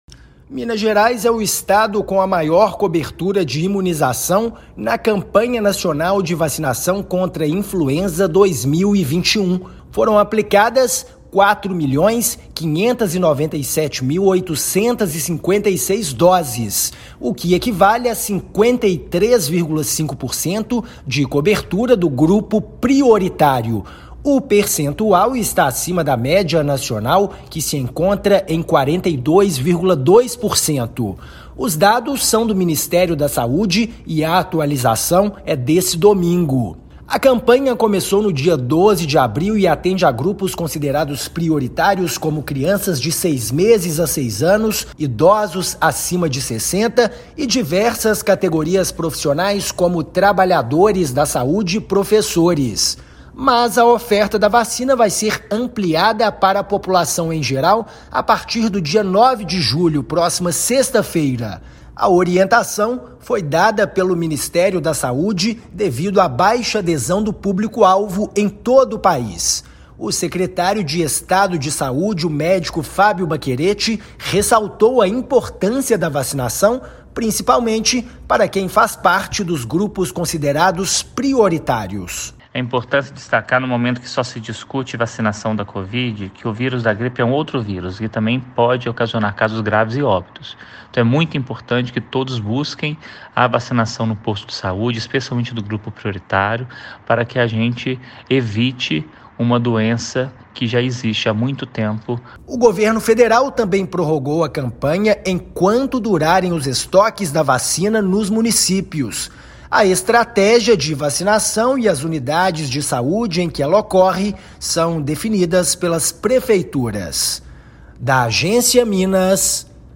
[RÁDIO] Minas Gerais é o estado com a maior cobertura na vacinação contra gripe
A partir de sexta-feira (9/7), imunização será ampliada para todos os mineiros. Ouça matéria de rádio.
MATÉRIA_RÁDIO_VACINAÇÃO_GRIPE.mp3